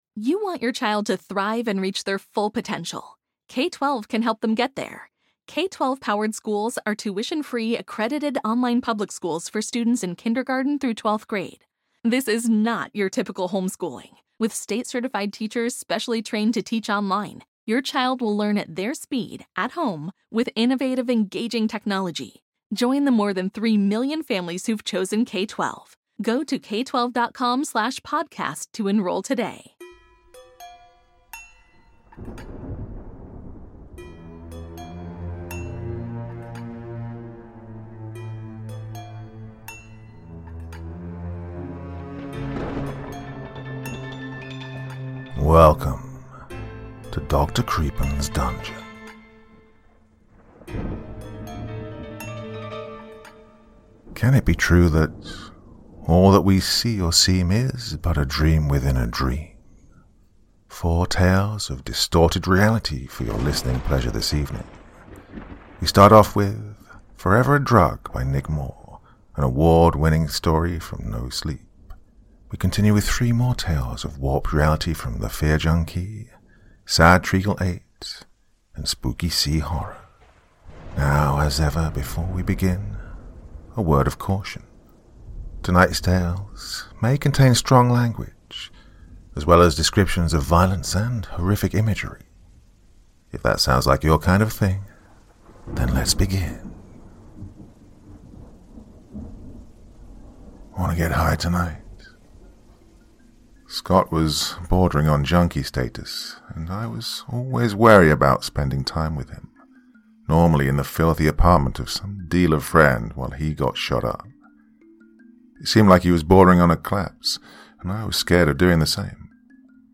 narrated here for you all